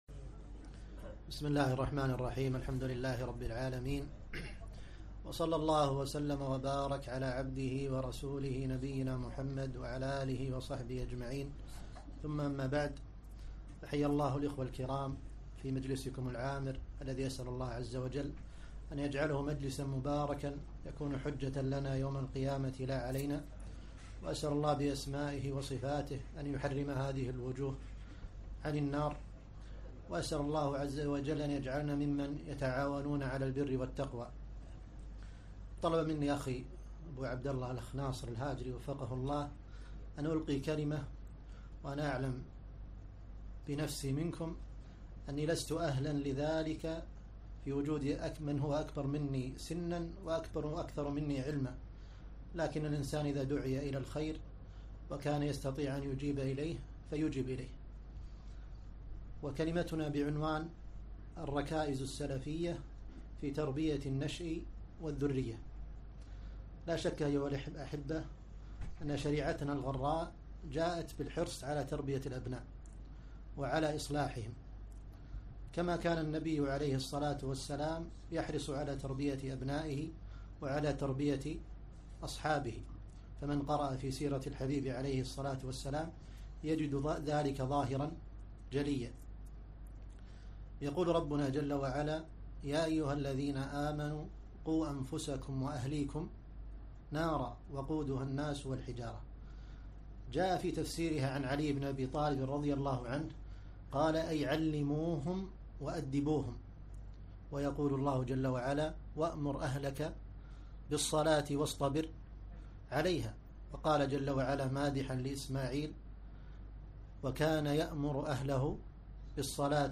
محاضرة - هدي السلف الصالح في تربية الأبناء